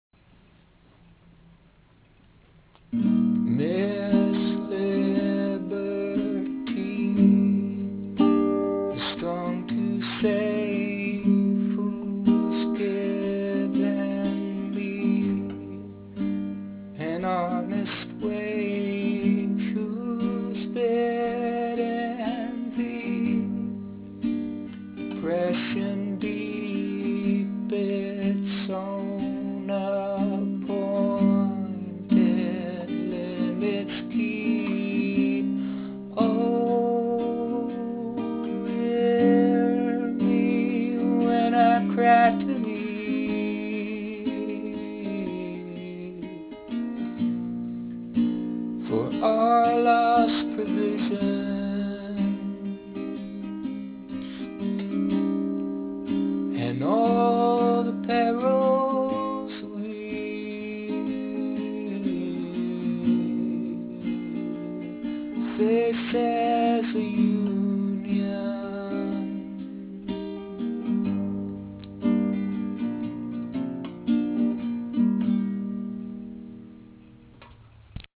verse (beginning): C, Am, Em, G; verse (end): Am, C7, F, G7